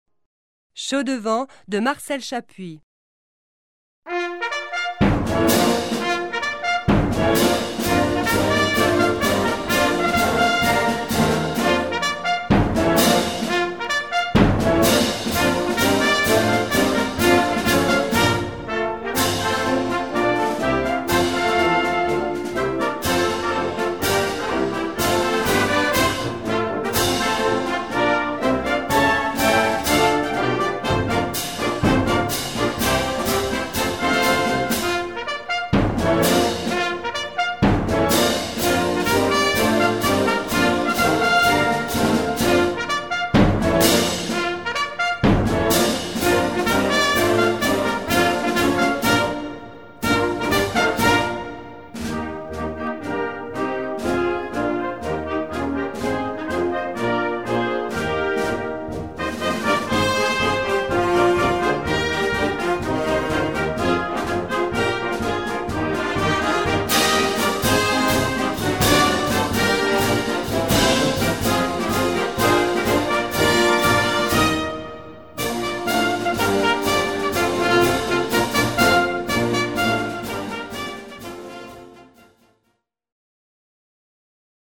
Répertoire pour Harmonie/fanfare - Défilé et parade